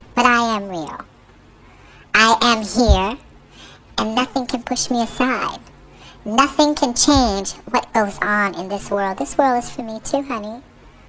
The changing of pitch of this recording, starting with the same pattern of distortion, relates to the changes of voice that occur during the process of transition, as well as serves as a recognition of trans musical artists who edit their voice’s pitch, today mainly seen in the hyperpop genre.
Pitched_Up_2.wav